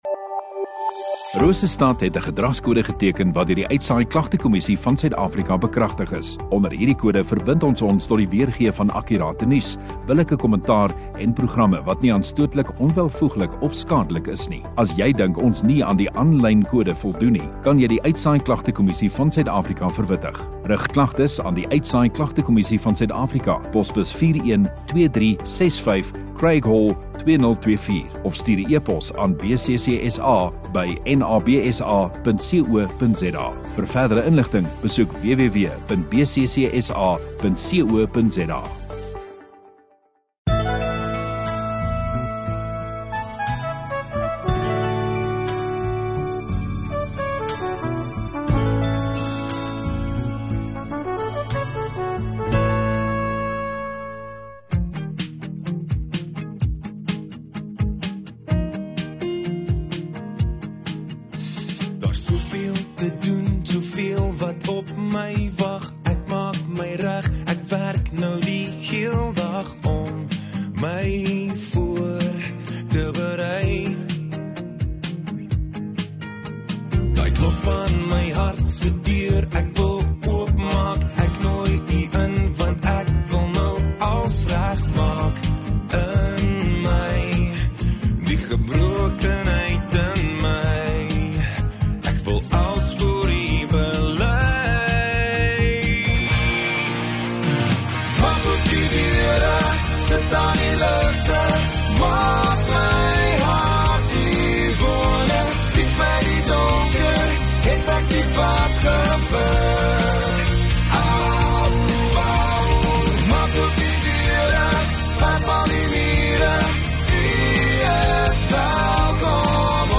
1 Jun Saterdag Oggenddiens